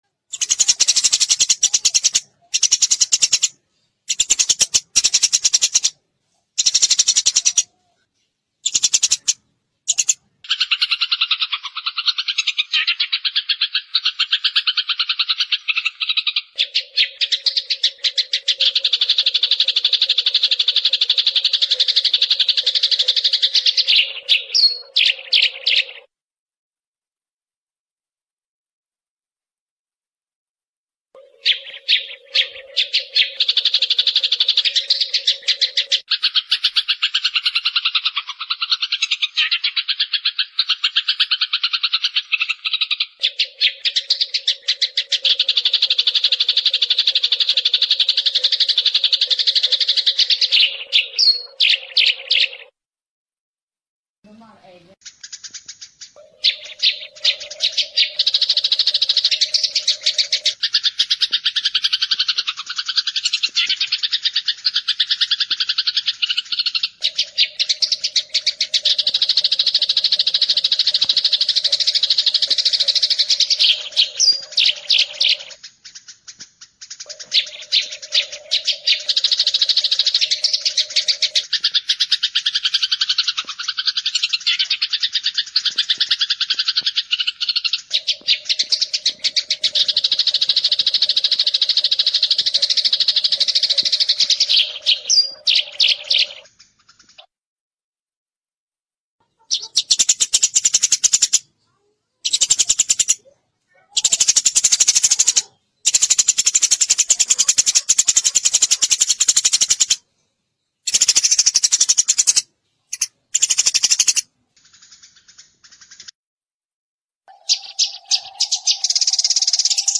Suara Burung Gereja Jantan
Kategori: Suara burung
Keterangan: Dapatkan suara burung gereja jantan panggil betina yang gacor full isian! Suara masteran burung gereja ini sangat ampuh dan efektif untuk membuat burung gereja Anda gacor dan ngeplong.
suara-burung-gereja-jantan-id-www_tiengdong_com.mp3